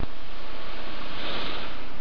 bfg_hum.ogg